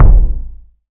Kicks
KICK.134.NEPT.wav